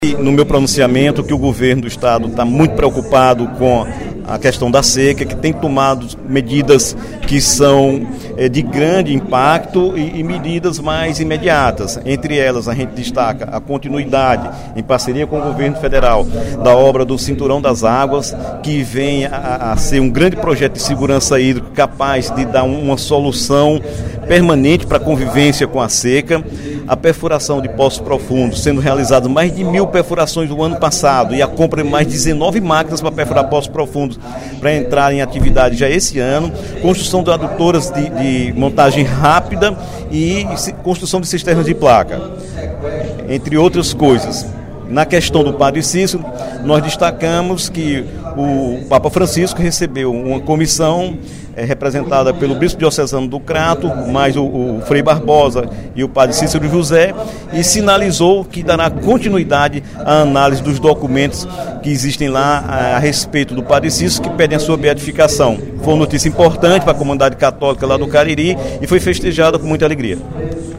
O deputado Dr. Santana ressaltou, no primeiro expediente da sessão plenária desta quinta-feira (16/06), as ações de grande impacto do Governo do Estado para a convivência com a seca.